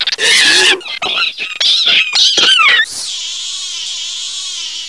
egg_burn.wav